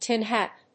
アクセントtín hát